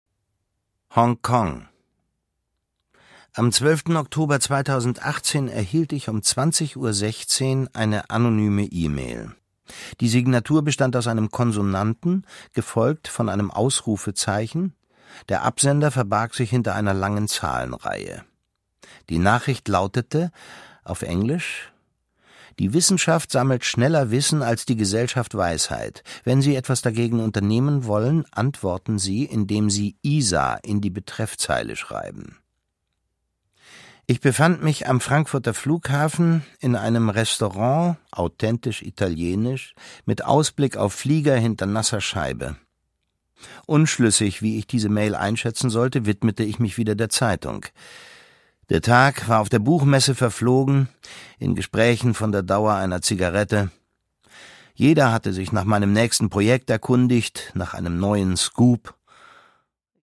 Produkttyp: Hörbuch-Download
Gelesen von: Thomas Sarbacher